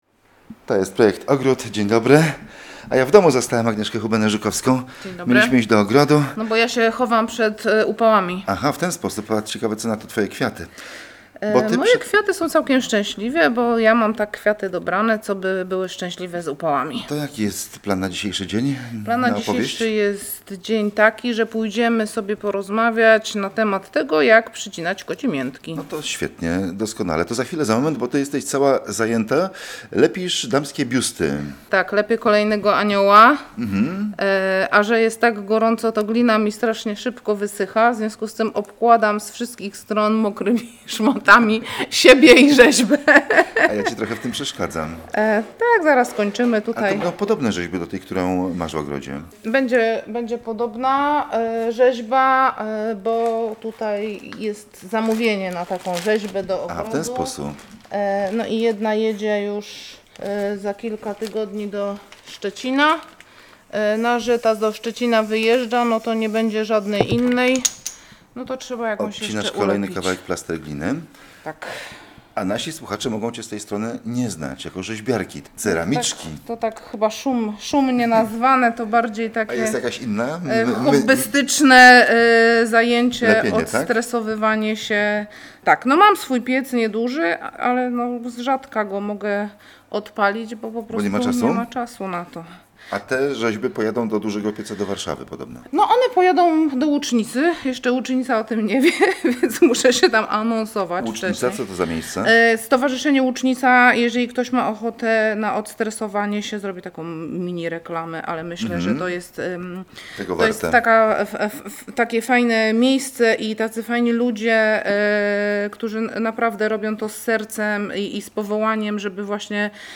A w plenerze projektantka opowiada o drobnych zabiegach na letnich rabatach kwiatowych.